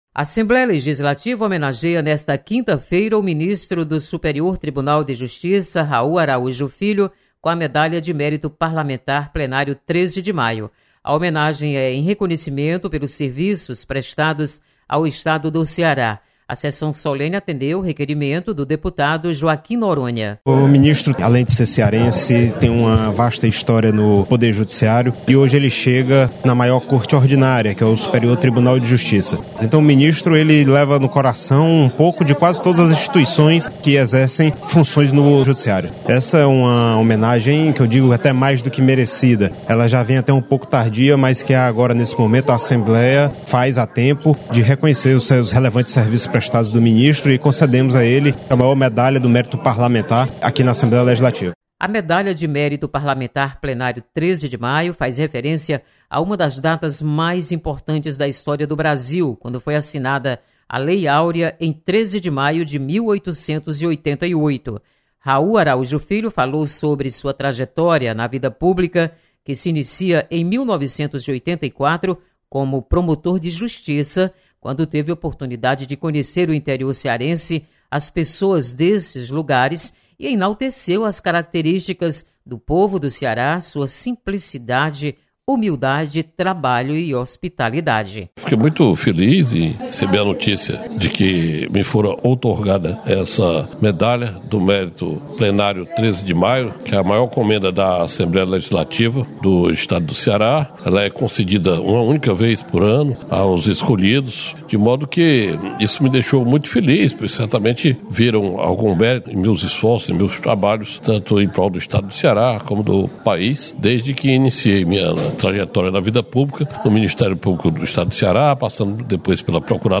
Você está aqui: Início Comunicação Rádio FM Assembleia Notícias Homenagem